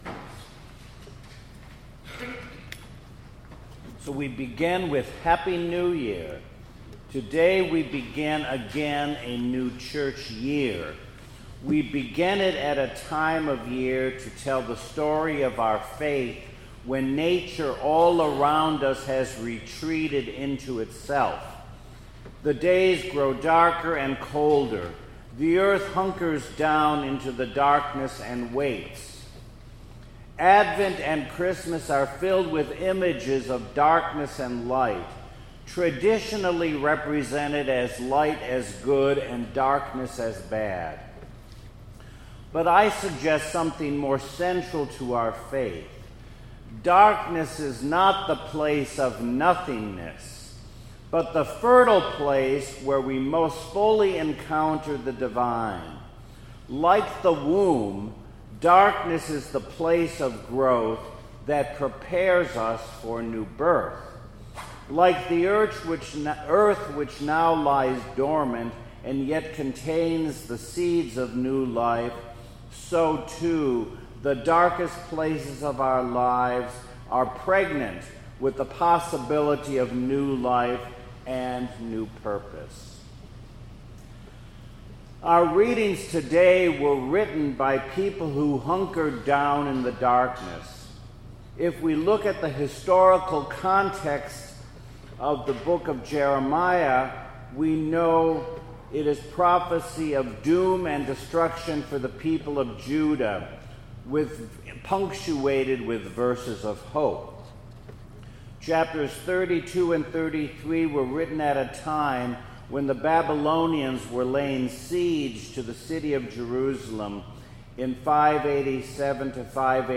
2018 Sunday Sermon